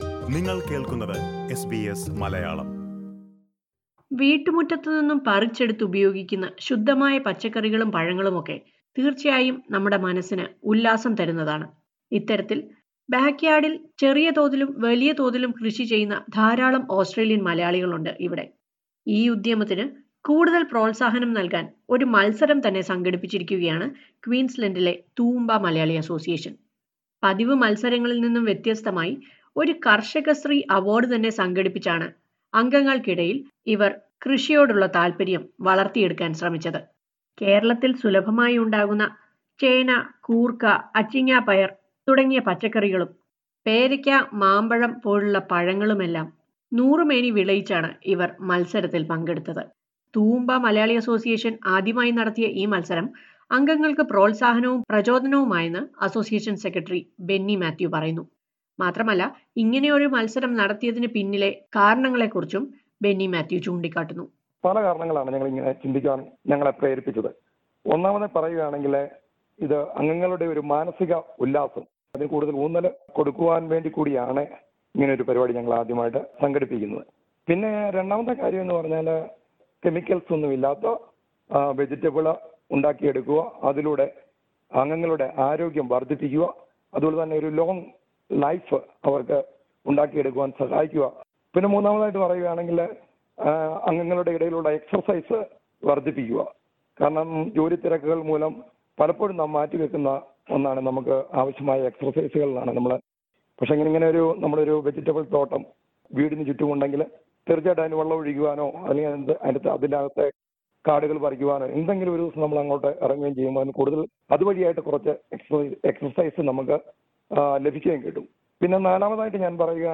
Toowoomba Malayalee Association held a Karshakasree Award for the members who are interested in backyard farming. Listen to a report on this...